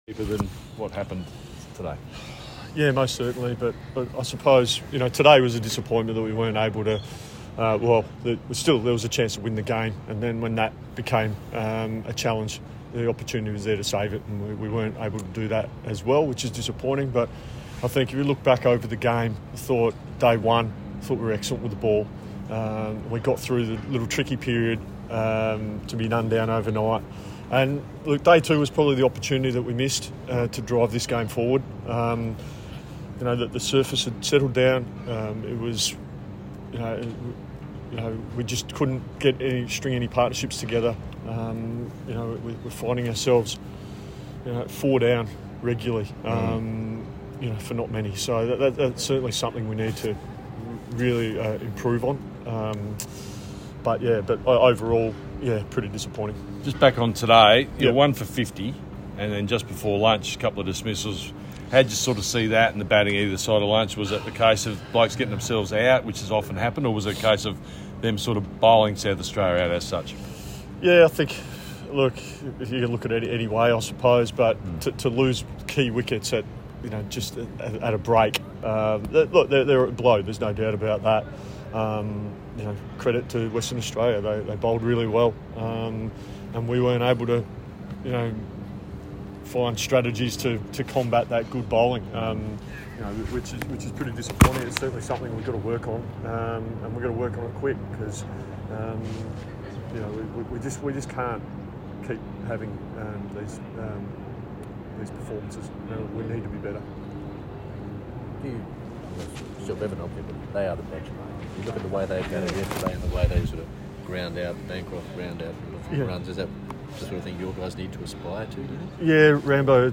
South Australia coach Jason Gillespie speaks after WA’s Shield win at Adelaide Oval